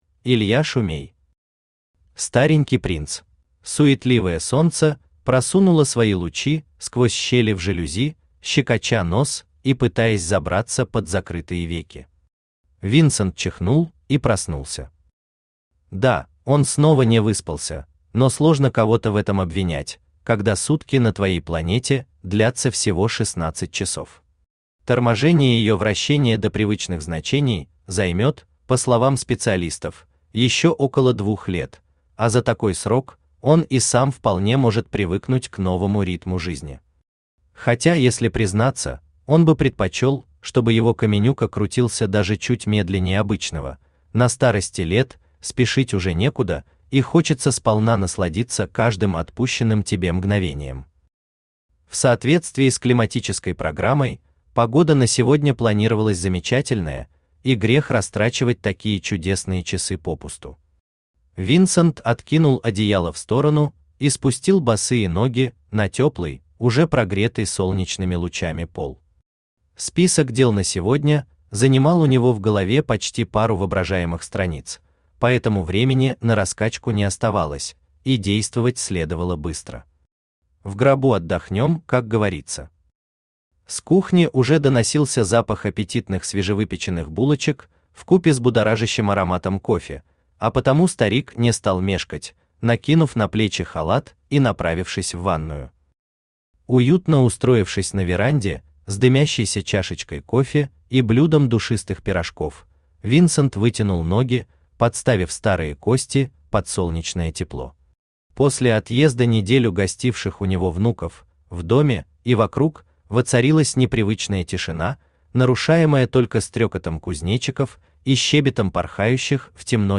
Аудиокнига Старенький Принц | Библиотека аудиокниг
Aудиокнига Старенький Принц Автор Илья Александрович Шумей Читает аудиокнигу Авточтец ЛитРес.